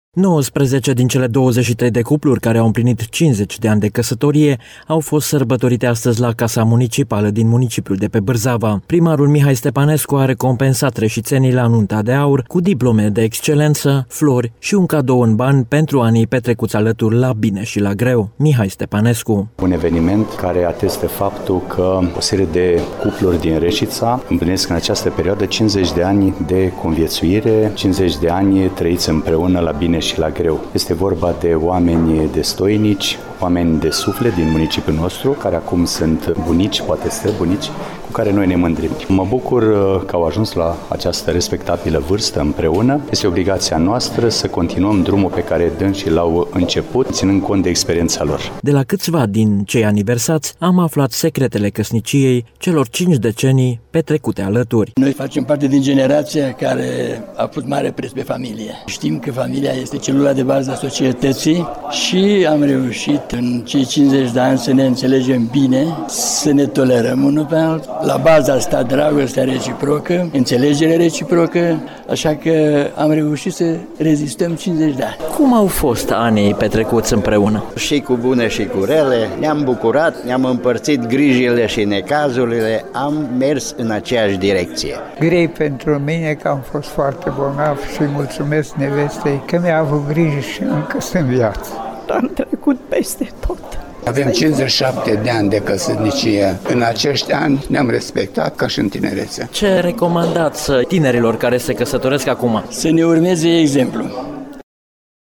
Zi aniversară pentru mai multe cupluri din municipiul de pe Bârzava, care au împlinit jumătate de secol de la căsătorie, au fost sărbătorite de primarul Mihai Stepanescu.